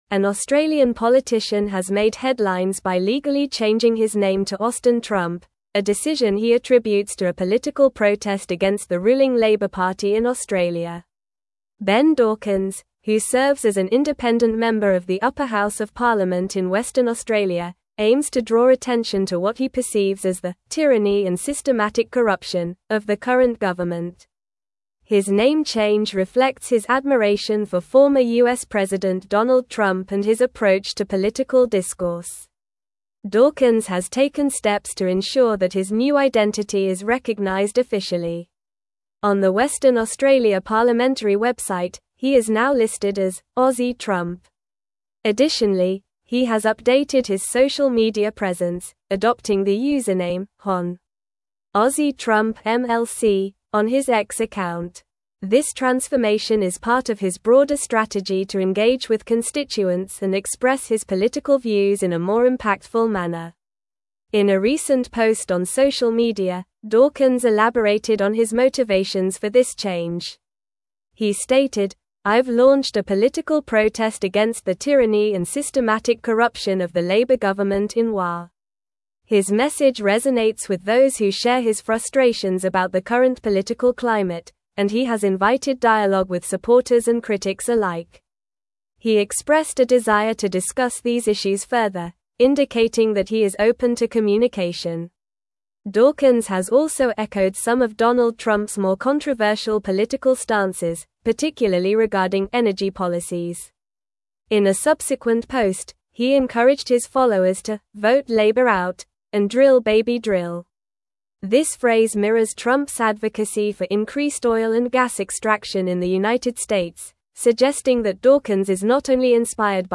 Normal
English-Newsroom-Advanced-NORMAL-Reading-Australian-Politician-Changes-Name-to-Austin-Trump.mp3